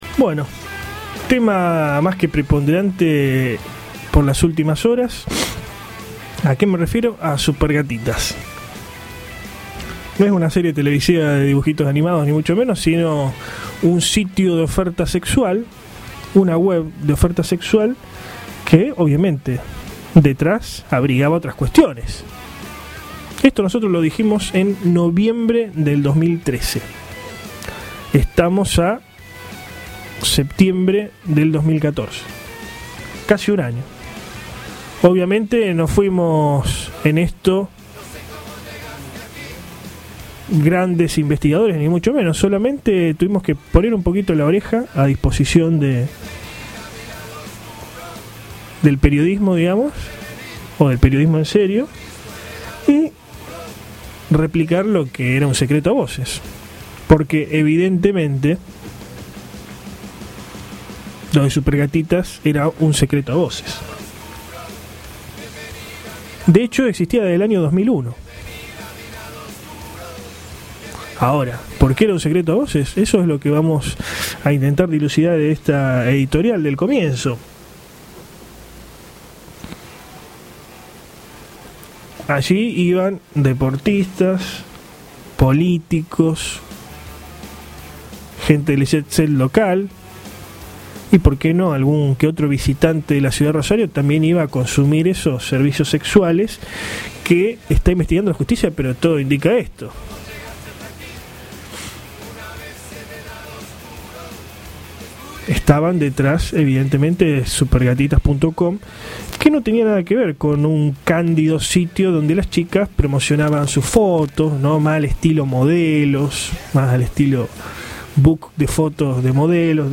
AUDIO EDITORIAL
editorial.mp3